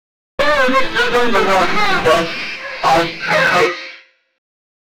Censorship Sound Effects - Free AI Generator & Downloads
Create and download royalty-free censorship sound effects.